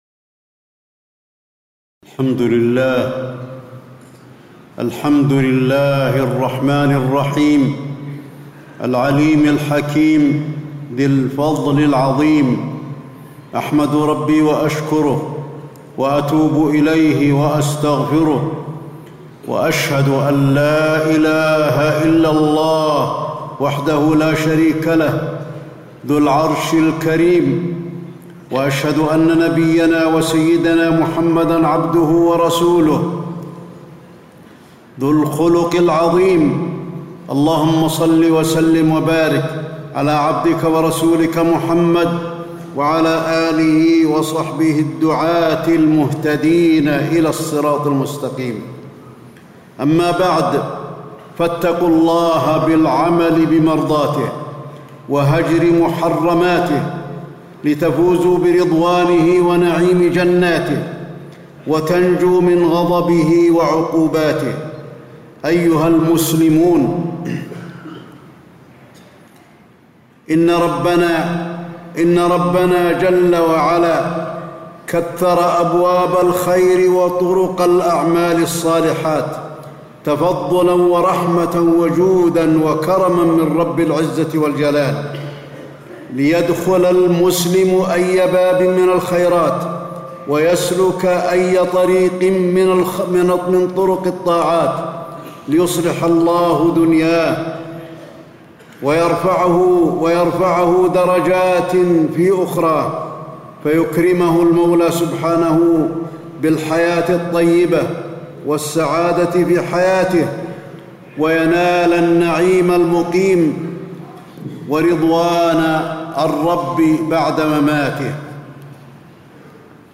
تاريخ النشر ١٠ محرم ١٤٣٧ هـ المكان: المسجد النبوي الشيخ: فضيلة الشيخ د. علي بن عبدالرحمن الحذيفي فضيلة الشيخ د. علي بن عبدالرحمن الحذيفي الاستغفار في الكتاب والسنة